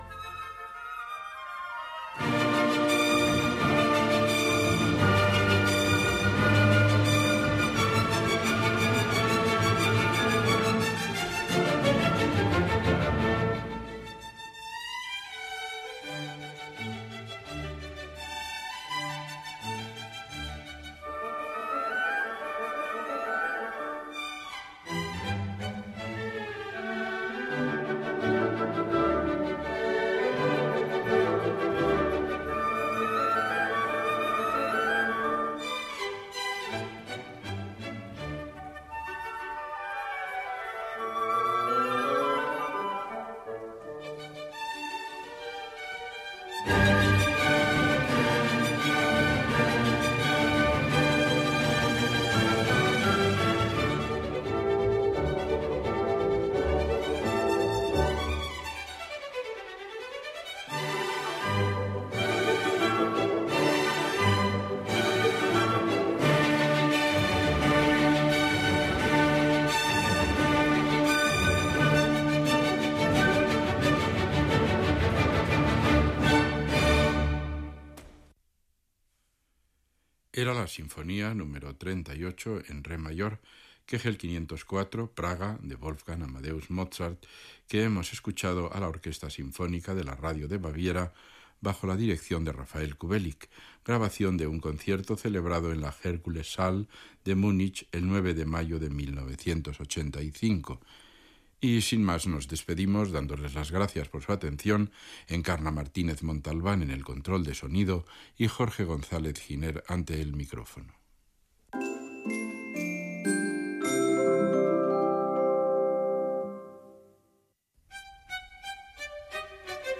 Tema musical, comiat del programa, indicatiu i anunci de la transmissió d'un concert del Festival d'Estiu d'Euroradio des de Lucerna (Suïssa)
FM